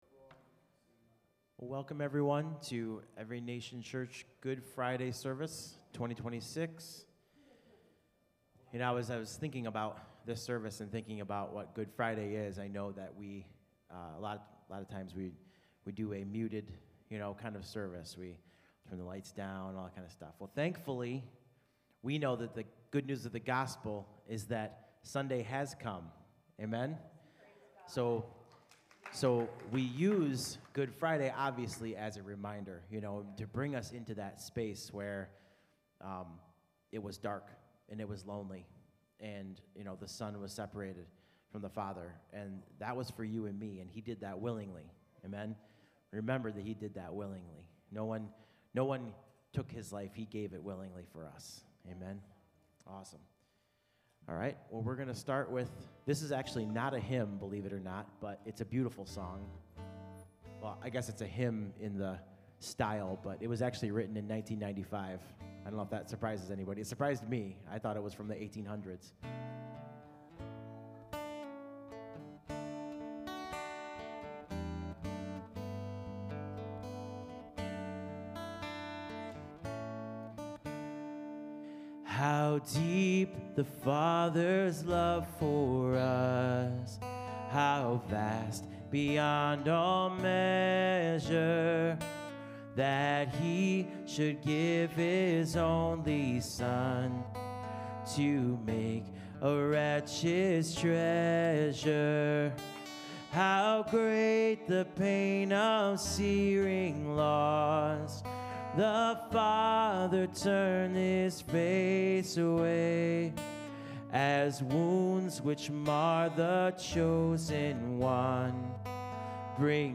Sunday Service 4-3-26 | Good Friday Service 2026